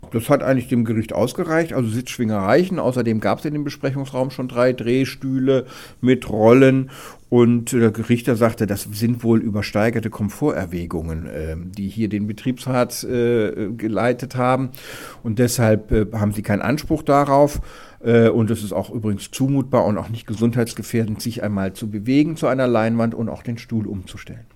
O-Ton: Betriebsrat bekommt keine Luxusstühle
DAV, O-Töne / Radiobeiträge, Ratgeber, Recht, , , , ,